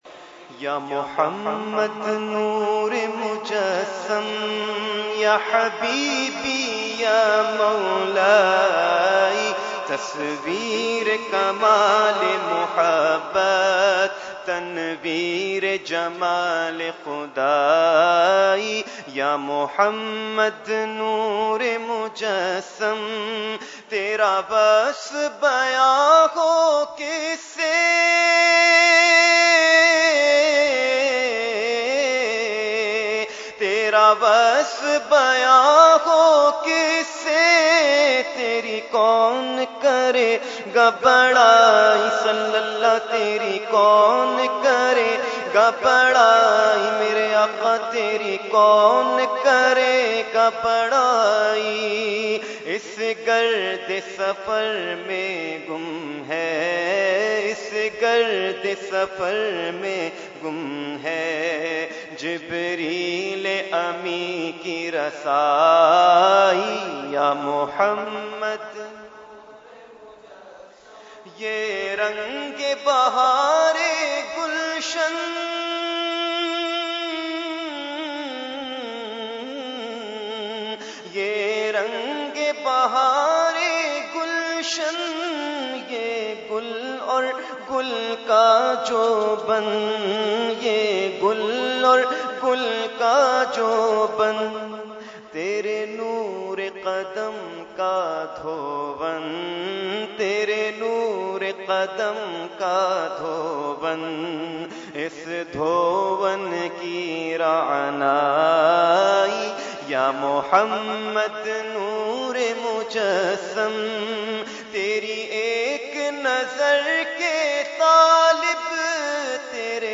Category : Naat | Language : UrduEvent : Khatam Hizbul Bahr 2017